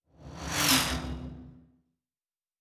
Sci-Fi Sounds / Movement / Fly By 07_3.wav
Fly By 07_3.wav